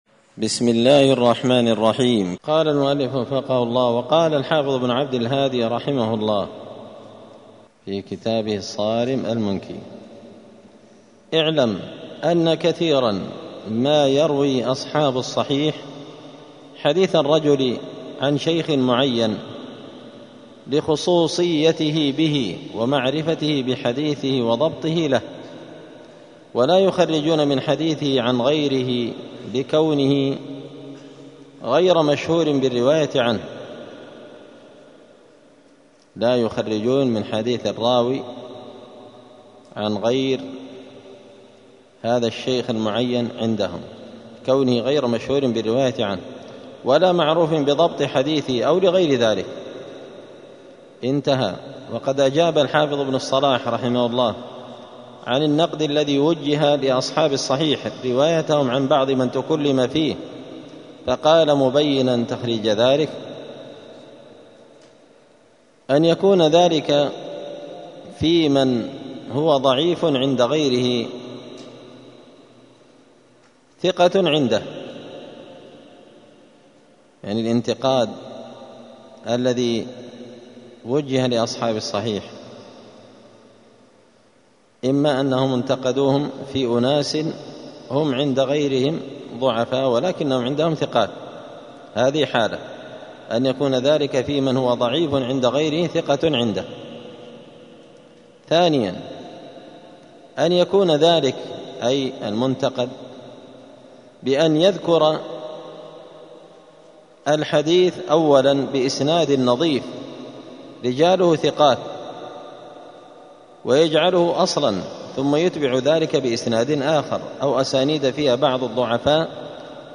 *الدرس التاسع والثلاثون (39) تابع لباب انتخاب صحيح حديث المتكلم في حفظه*
دار الحديث السلفية بمسجد الفرقان بقشن المهرة اليمن